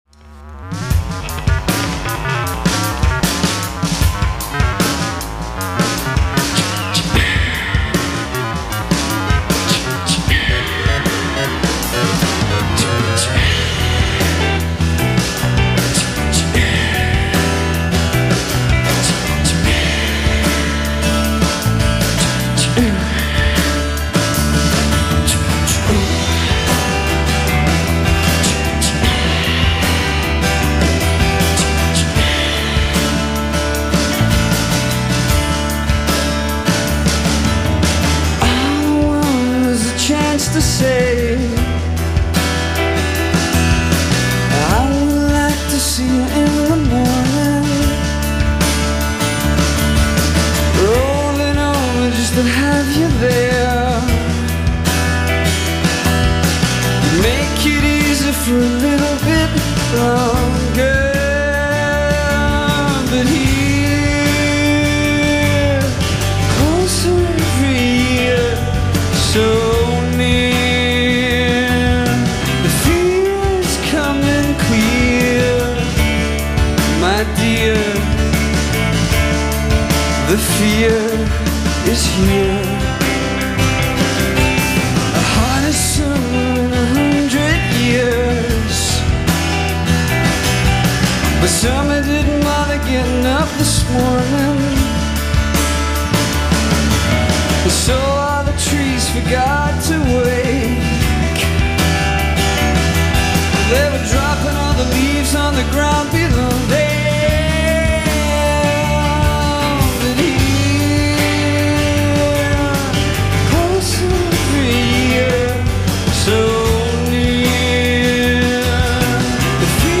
One of the early influential bands in the Brit-Pop movement